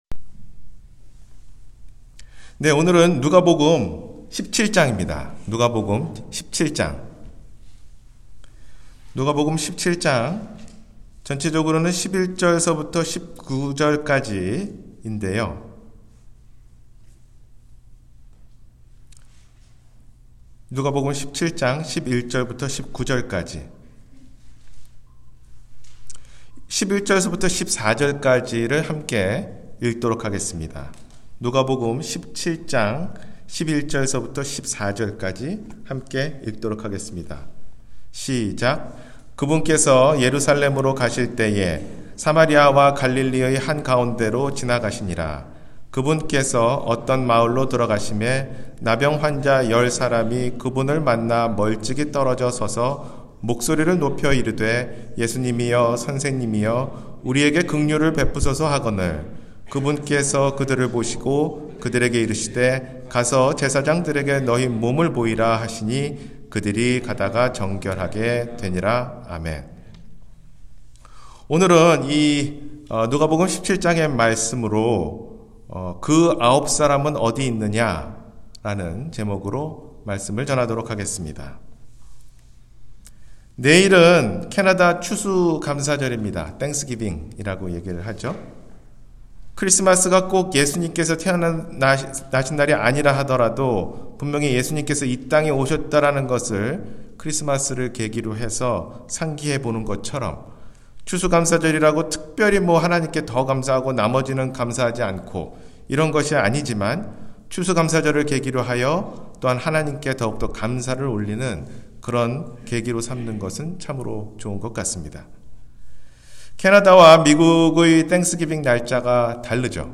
그 아홉 사람은 어디 있느냐? – 주일설교